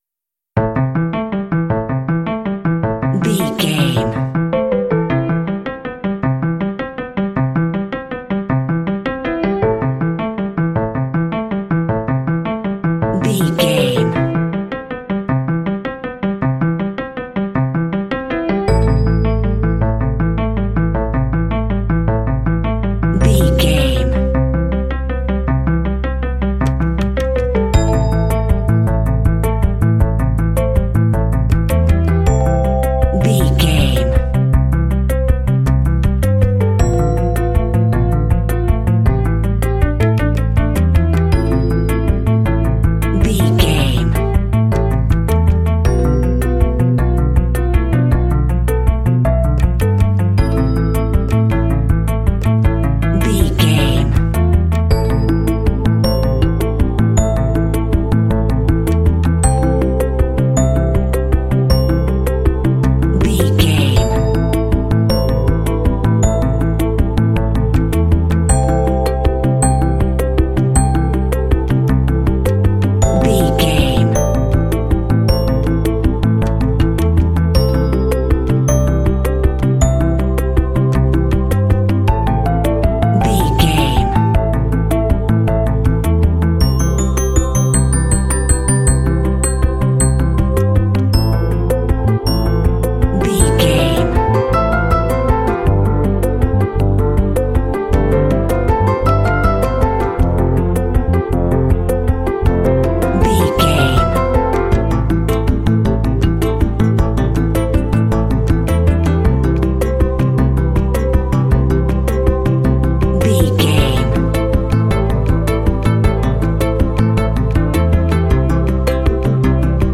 Ionian/Major
mystical
futuristic
piano
synthesiser
drums
contemporary underscore